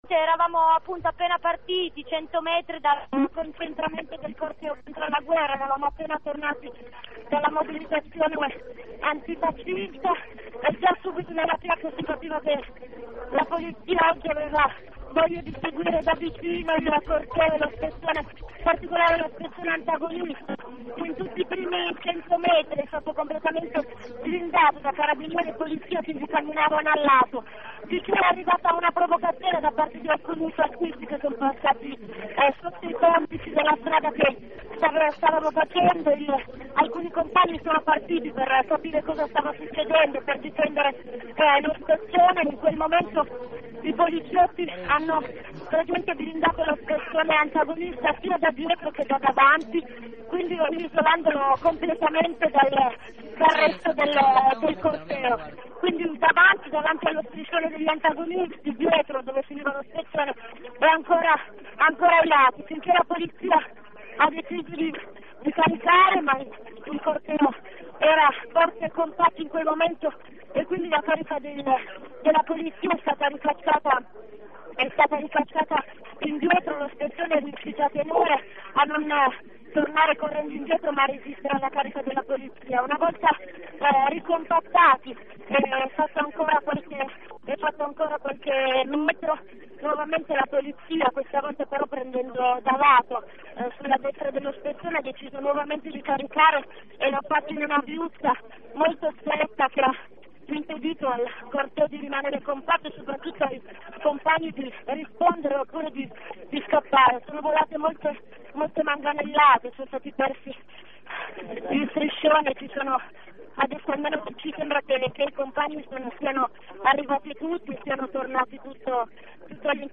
prima carica al corteo antifascista